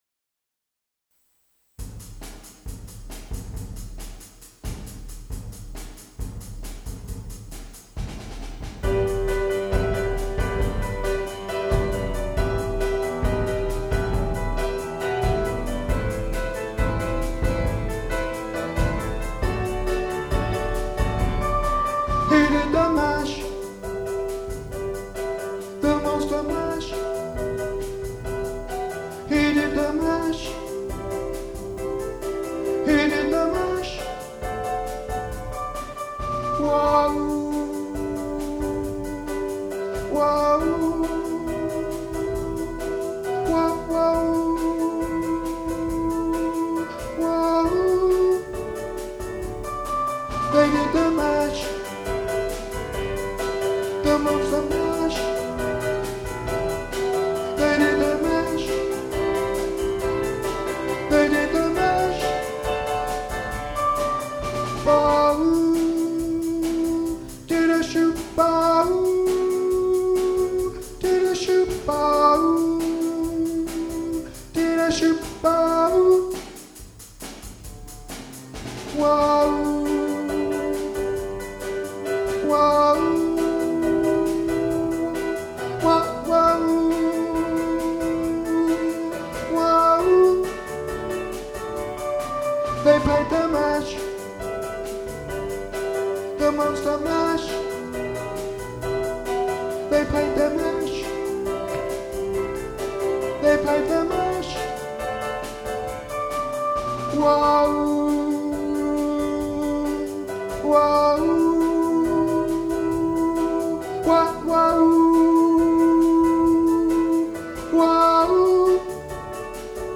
Monster Mash Alto 1 | Ipswich Hospital Community Choir